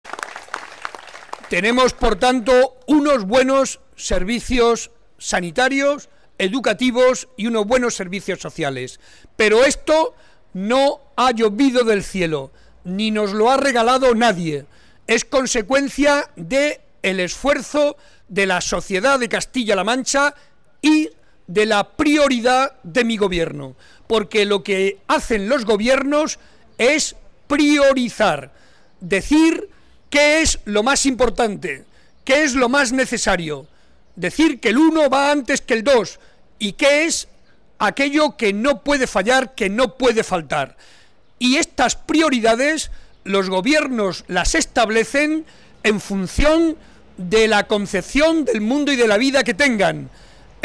Barreda hacía estas declaraciones en el paseo de la Constitución de la localidad, en la que el Gobierno de Castilla-La Mancha ha invertido 18 millones de euros en proyectos tales como la sección de secundaria; el EDAR Chinchilla-Alpera-Bonete o el Punto Limpio.